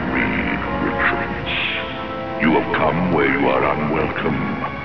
From the Fantastic Four animated series.